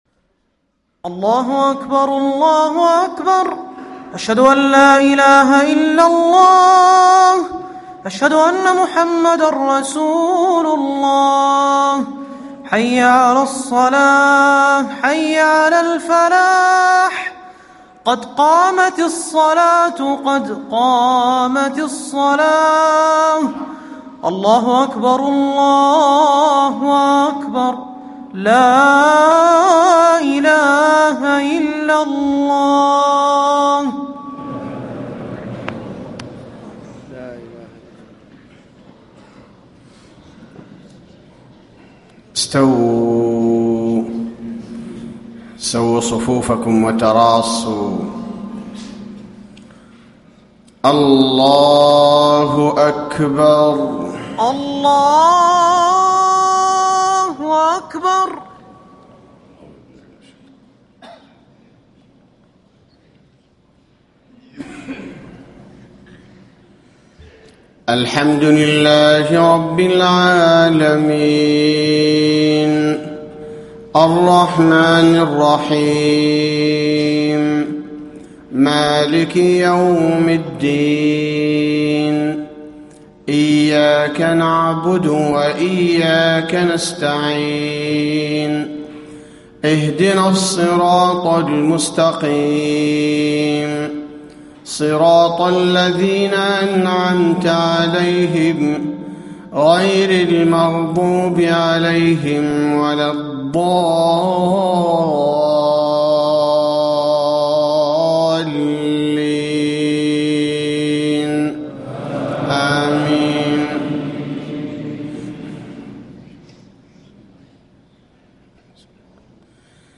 صلاة الفجر 1 - 3 - 1434هـ سورتي الفجر و البلد > 1434 🕌 > الفروض - تلاوات الحرمين